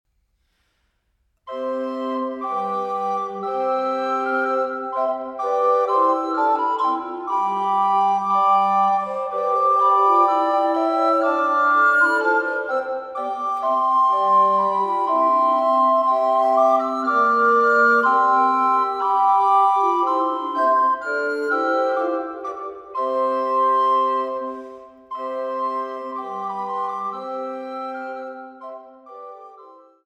Fünfstimmiges Blockflötenconsort